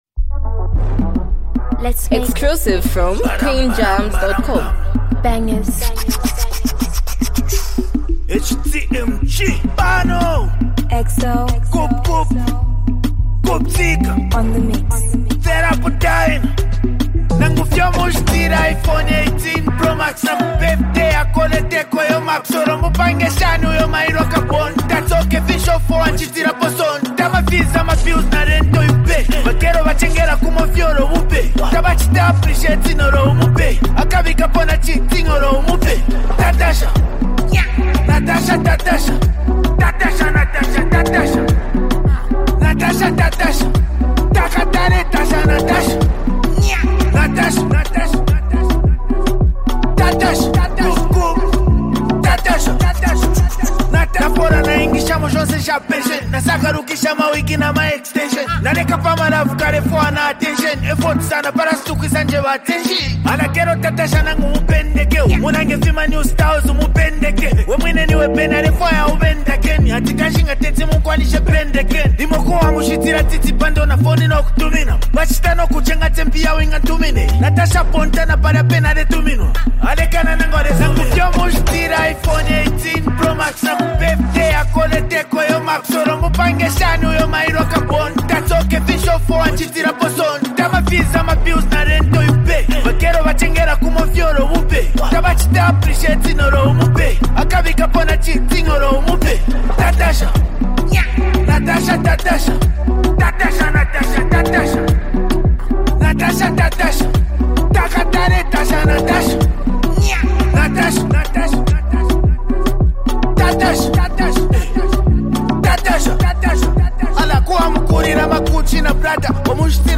vibrant and catchy track
smooth Zambian urban sound